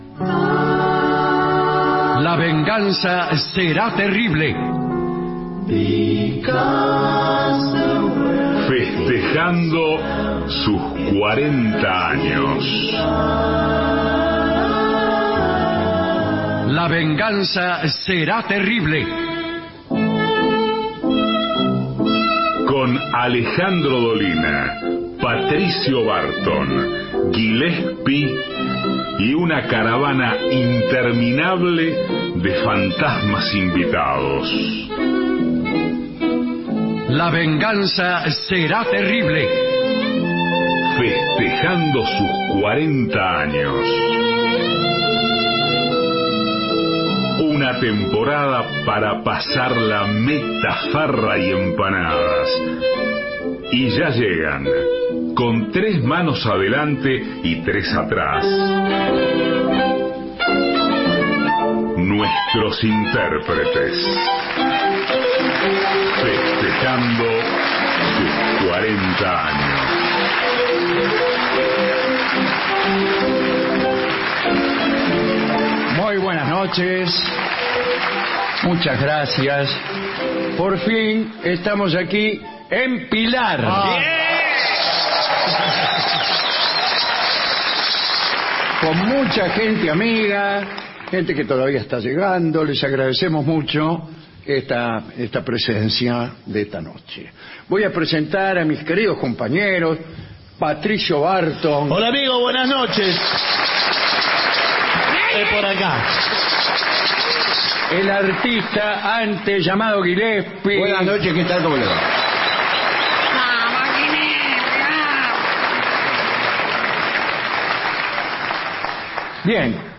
Teatro Gran Pilar, Pilar